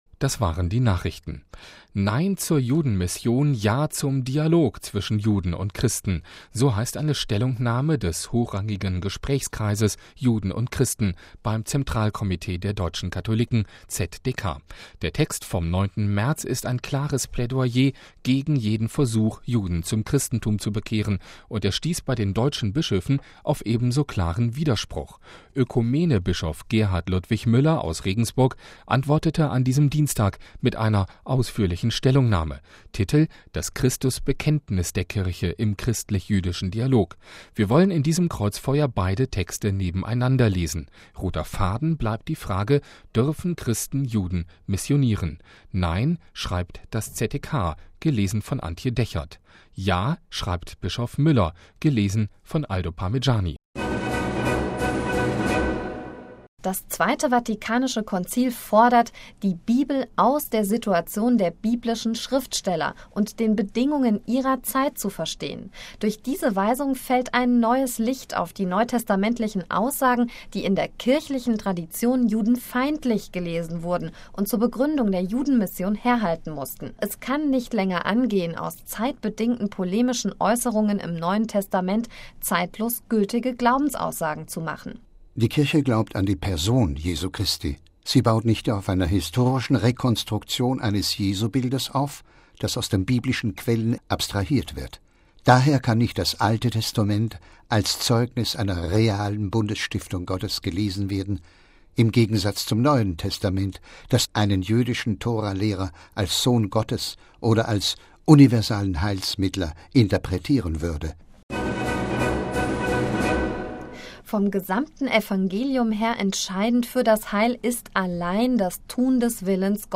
Pro und contra Judenmission - ein Audio-Feature